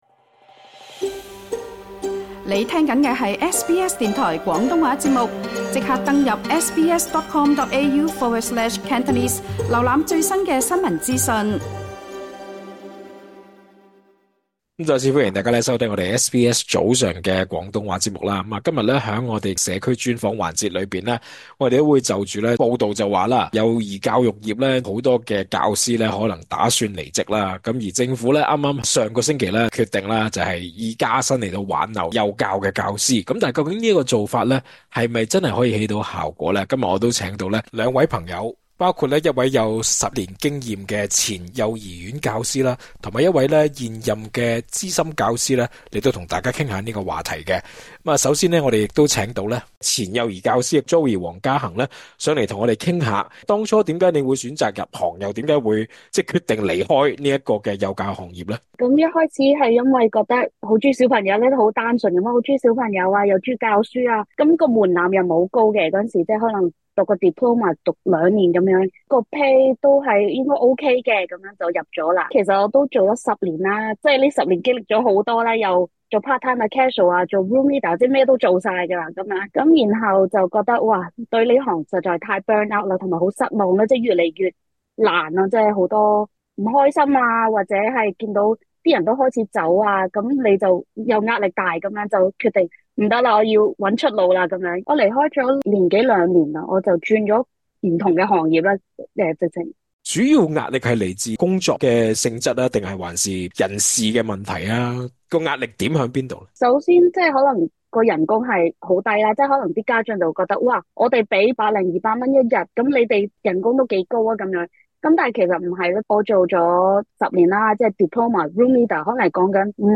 詳盡訪問：